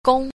b. 宮 – gōng – cung
gong.mp3